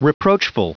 Prononciation du mot reproachful en anglais (fichier audio)
Prononciation du mot : reproachful